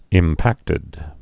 (ĭm-păktĭd)